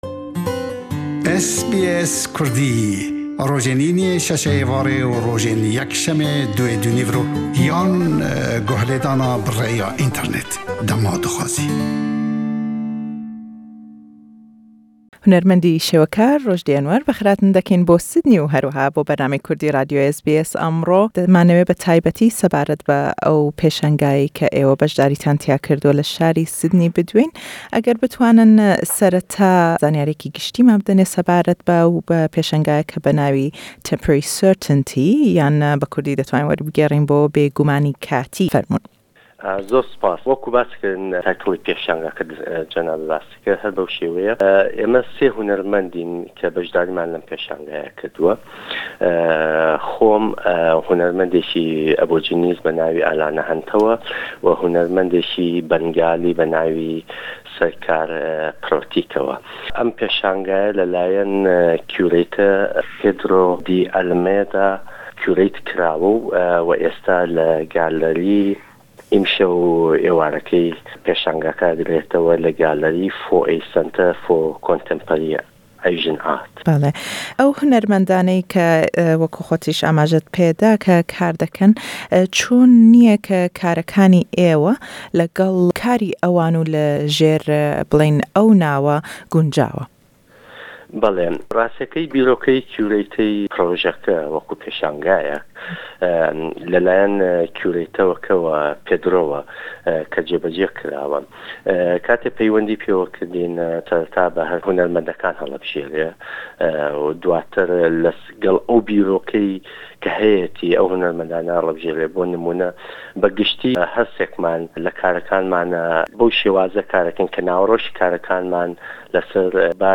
Le em lêdwane da